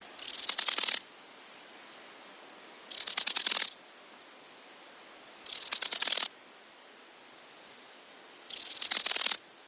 Акустические сигналы: одиночный самец, Монгольская Народная Республика, Убсу-Нурский аймак, хребет Хан-Хухий, запись
Температура записи 28-30° С.